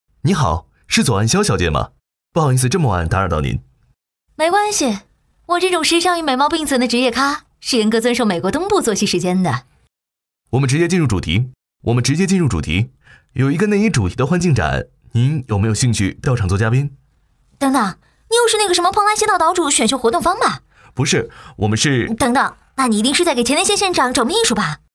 女29-角色扮演【爱慕-男女29对播】
女29-大气磁性 轻松活泼